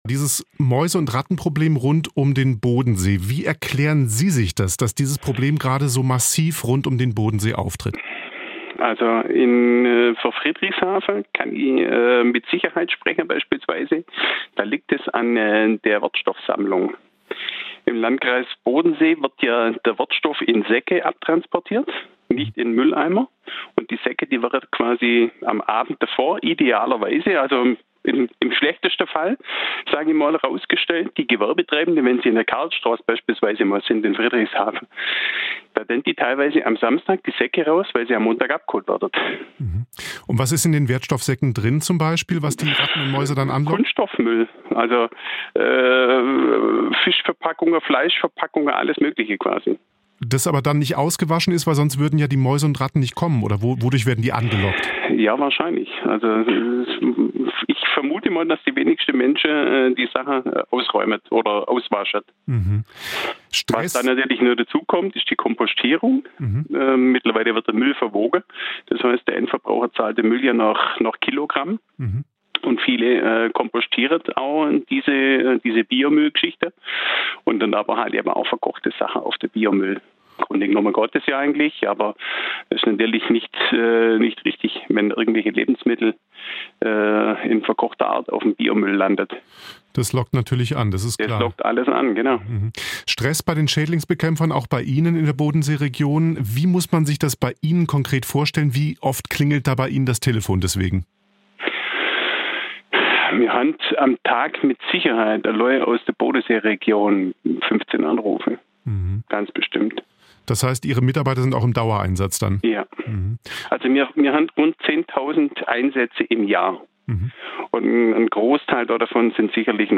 Der SWR hat mit ihm ein Telefon-Interview geführt.